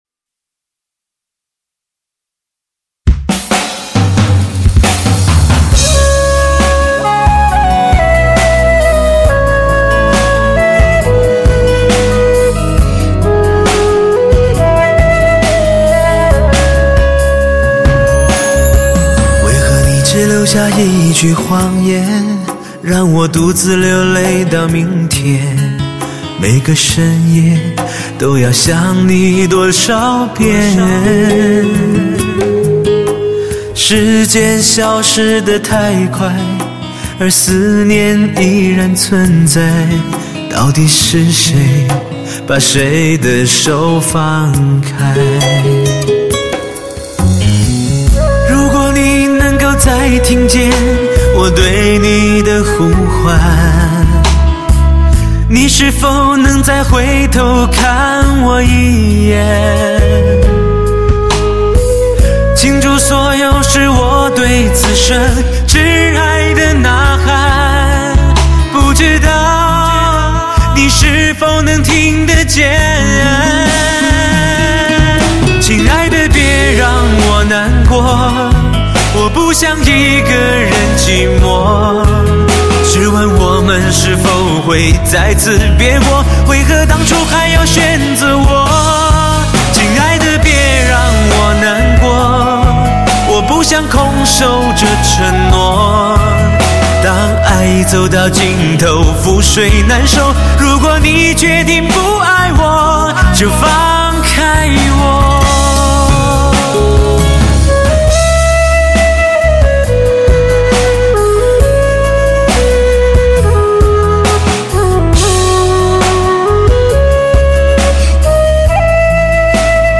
汽车专业发烧大碟
极致人声 发烧音效 聆听极品
令人欲罢不能的音效，极具穿透力，将你带入超乎完美的发烧音乐境界……